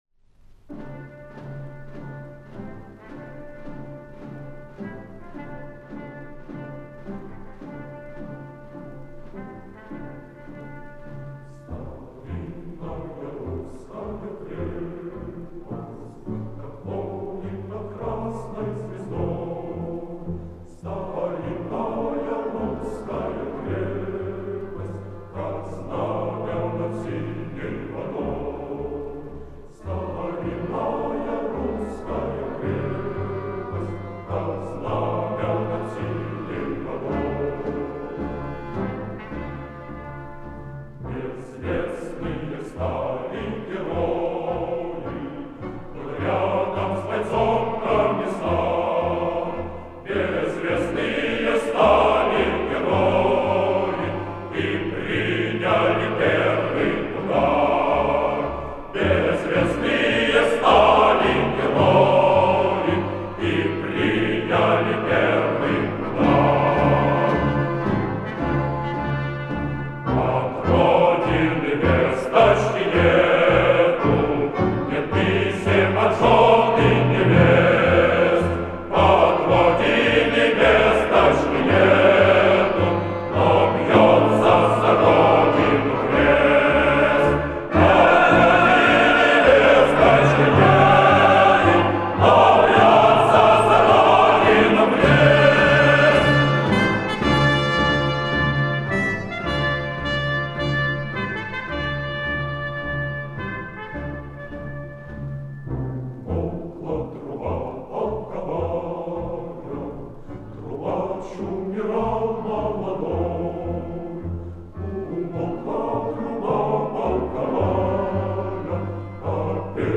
Песня и запись 1960-х гг.